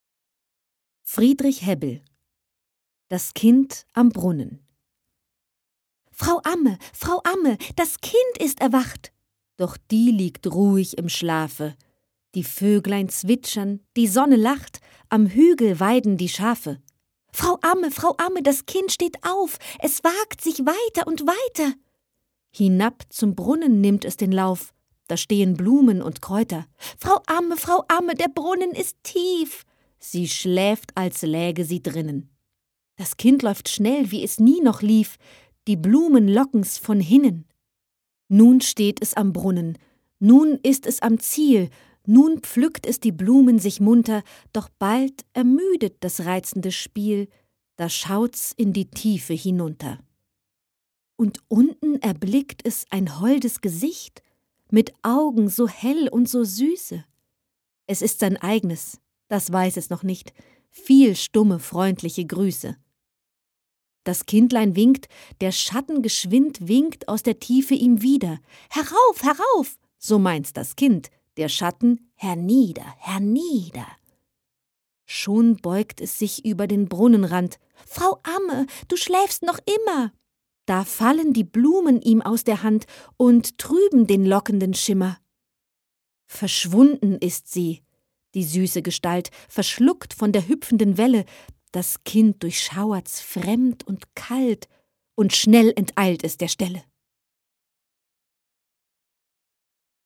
Balladen, Lyrik